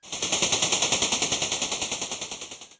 Machine14.wav